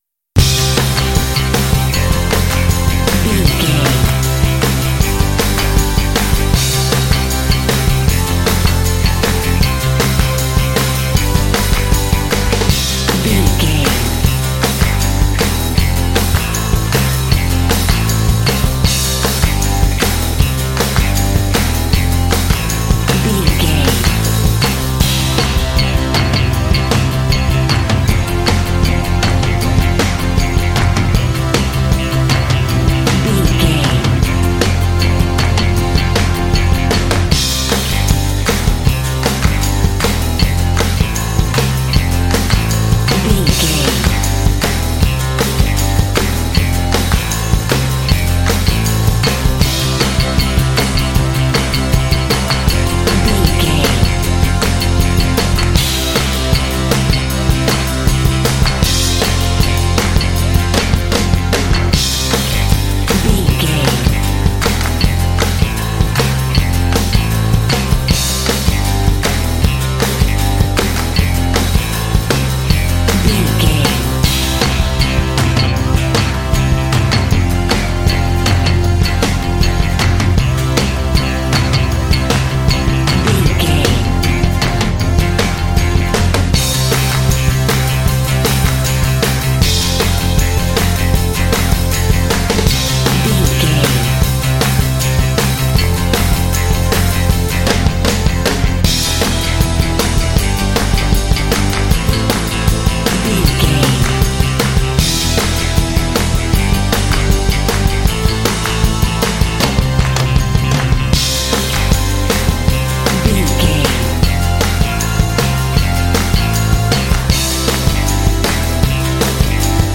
This lively rock track is ideal for action and sports games.
Uplifting
Ionian/Major
Fast
driving
bouncy
energetic
bass guitar
electric guitar
synthesiser
classic rock